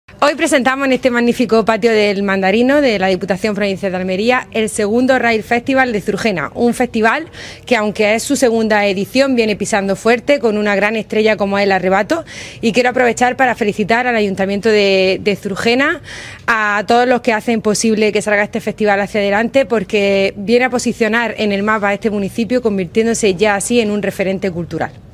La vicepresidenta de Cultura, Cine e Identidad Almeriense, Almudena Morales; y el alcalde de Zurgena, Domingo Trabalón, han presentado esta edición.